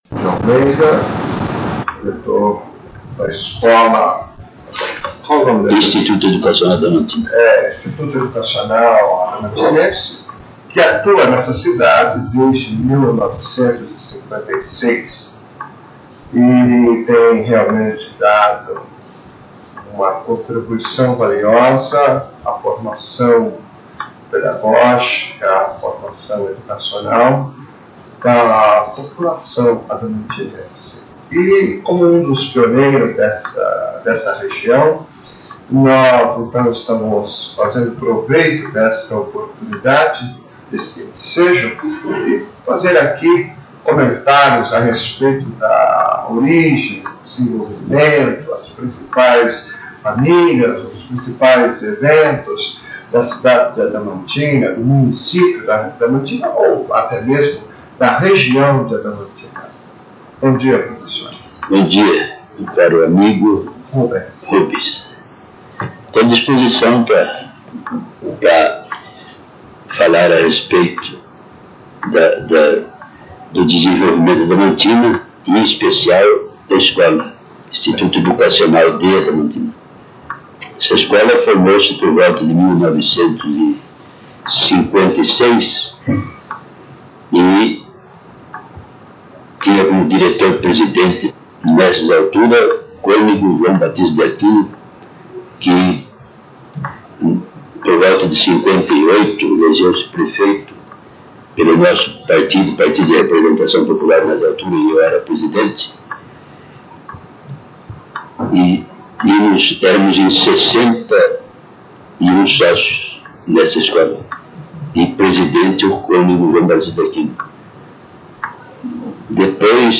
Entrevista com Sebastião de Almeida – Pioneiro, educador e político